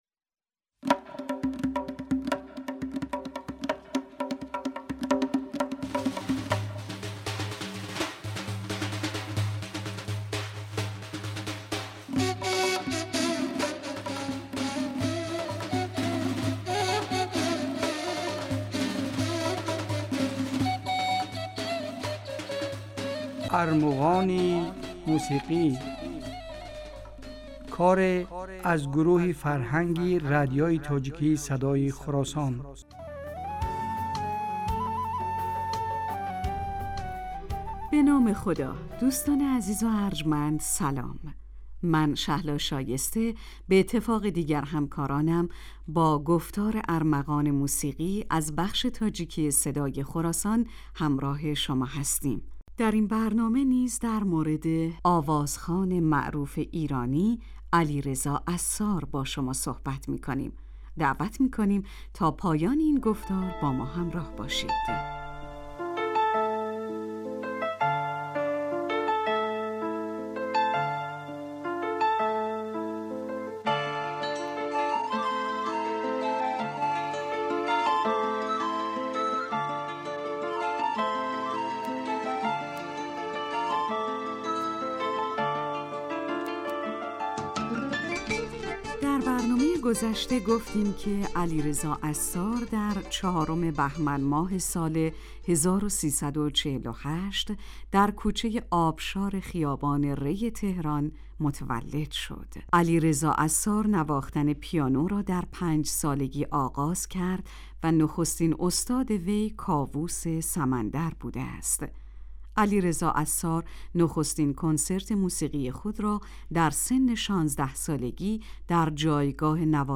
Армуғони мусиқӣ асари аз гурӯҳи фарҳанги радиои тоҷикии Садои Хуросон аст. Дар ин барномаҳо кӯшиш мекунем, ки беҳтарин ва зеботарин мусиқии тоҷикӣ ва эрониро ба шумо пешкаш кунем.